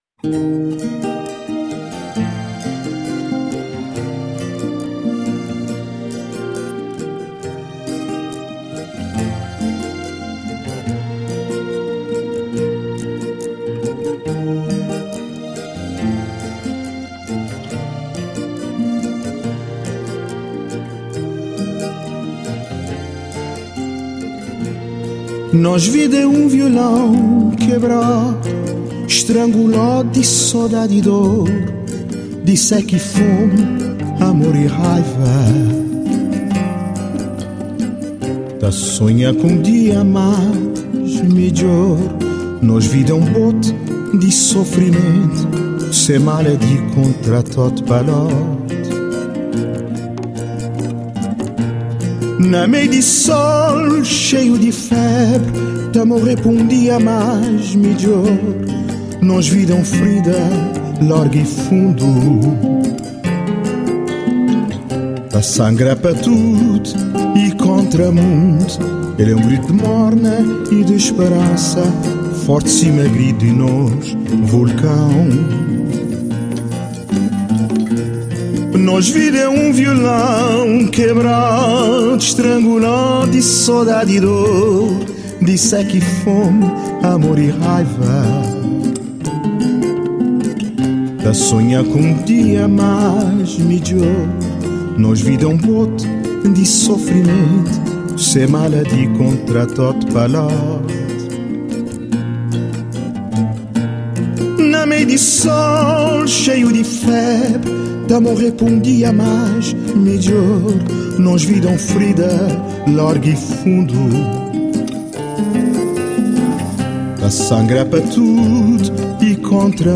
morna